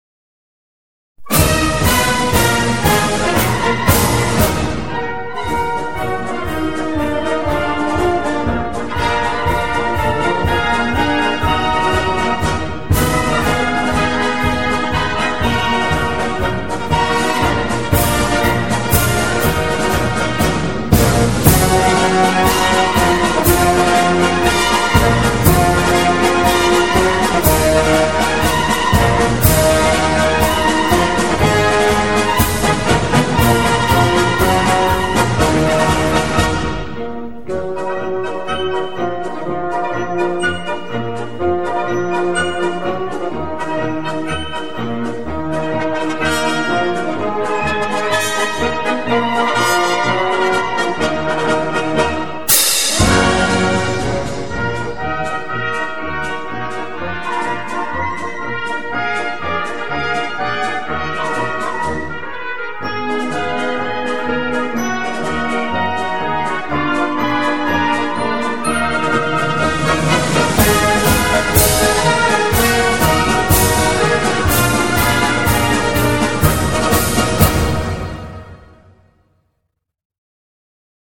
В.Агапкин Марш ''Прощание славянки''.MP3